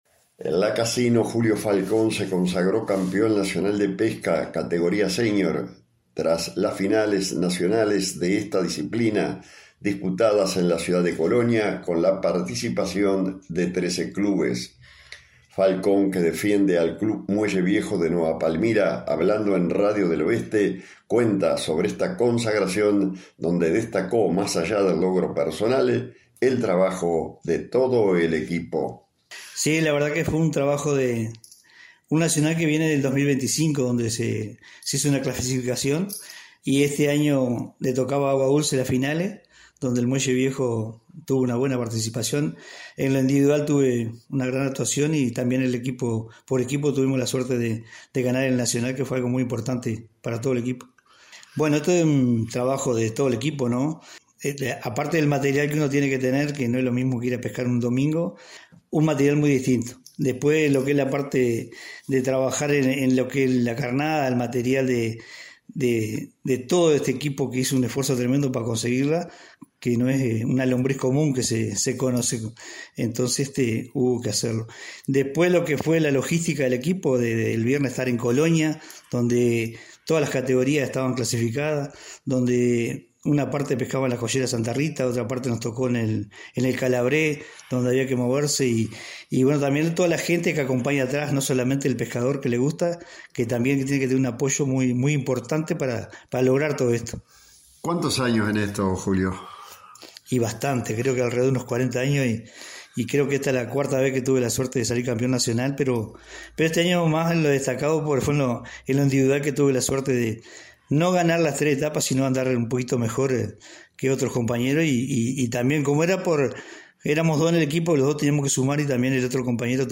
en diálogo con Radio del Oeste